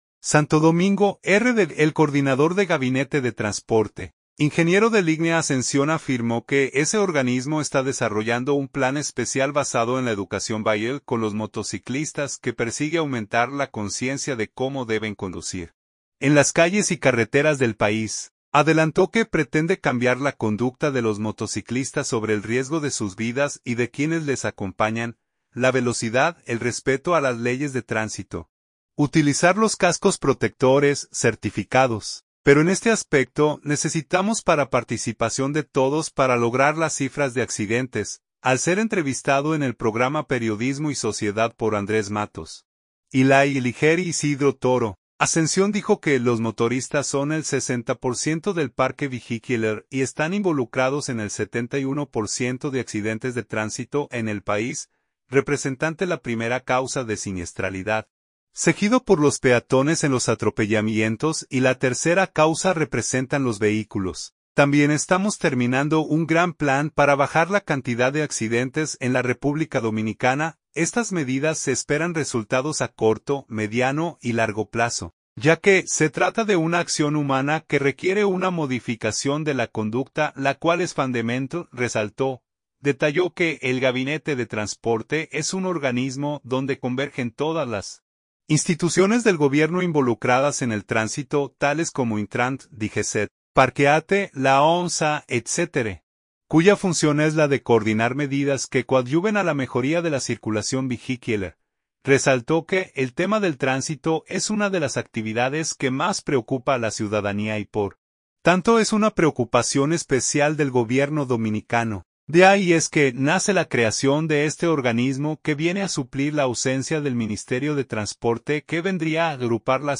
Al ser entrevistado en el programa Periodismo y Sociedad